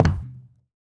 bowling_ball_land_01.wav